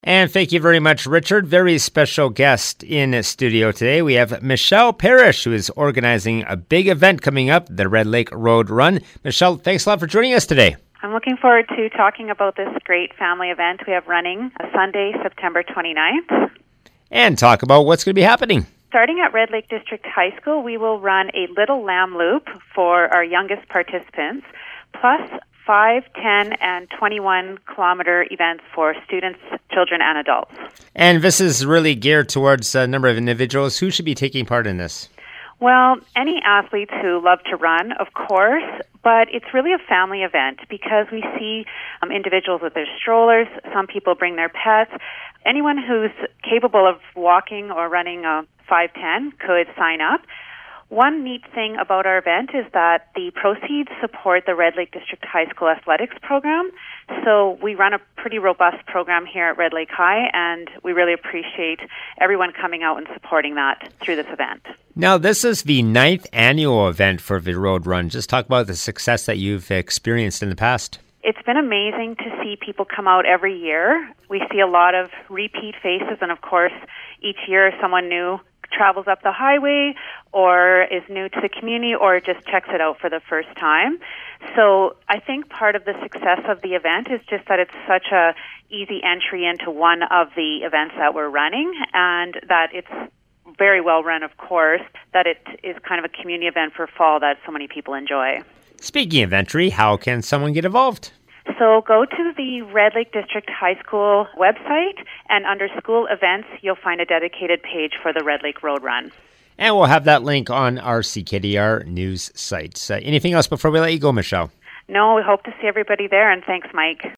was a guest on the CKDR Morning Show today (Thursday) to promote the run.